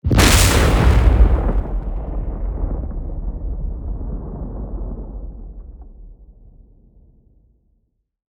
explosion_sound.wav